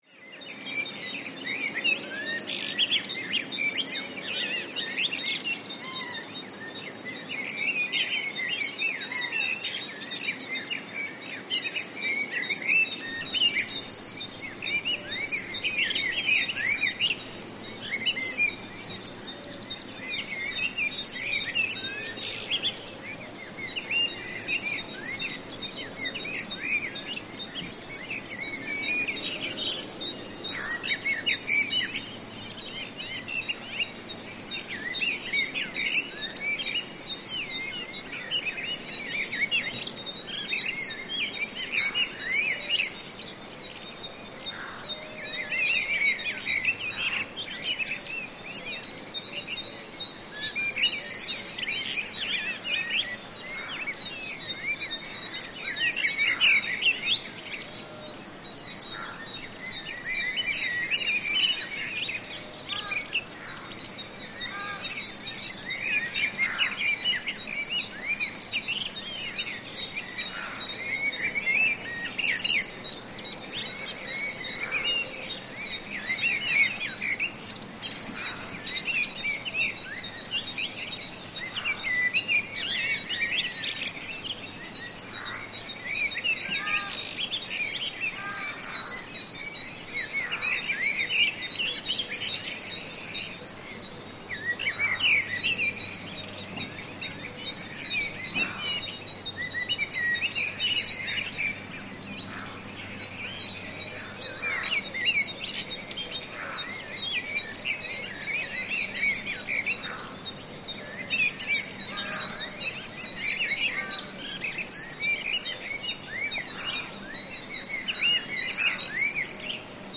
Early Morning Birds, Occasional Sheep In Distance